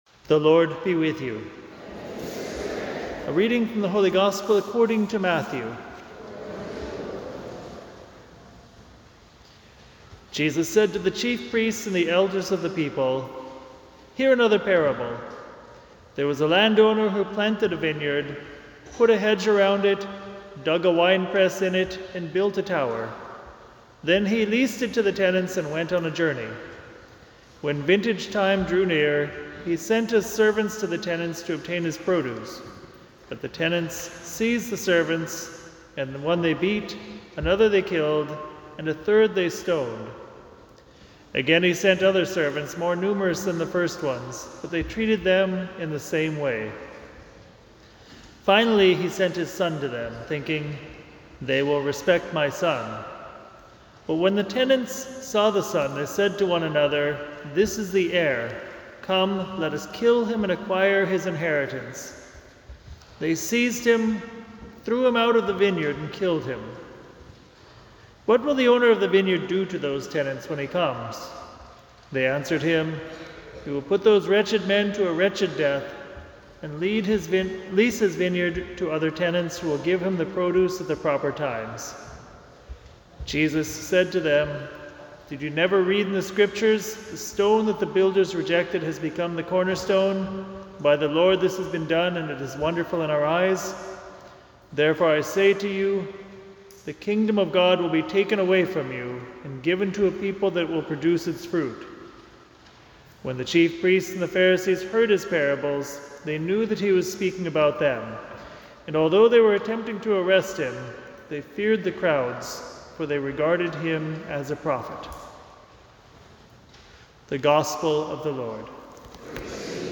Family Friday Homily
March 1st at Church of the Resurrection in Rye, NY for the children and families of Resurrection Grammar School.